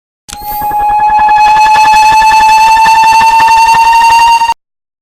payday-2-sound-cloaker-scream_SY1Zfbg.mp3